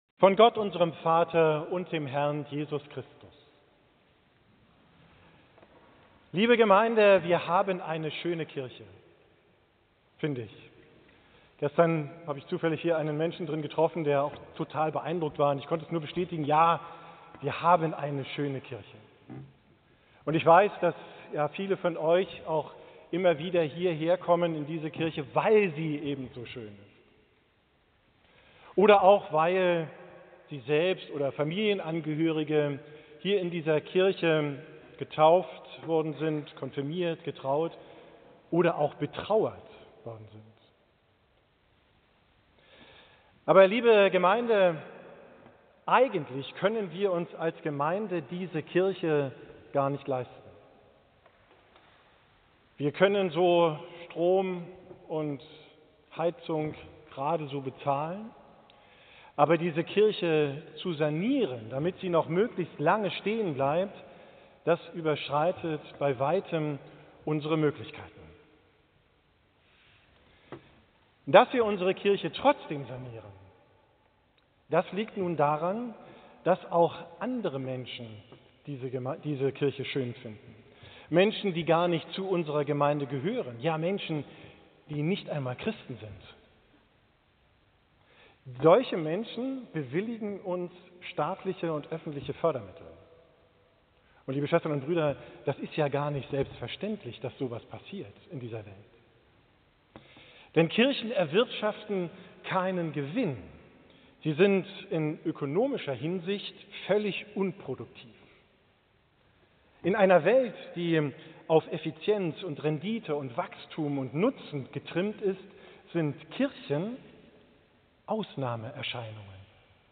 Predigt von Christi Himmelfahrt, 29.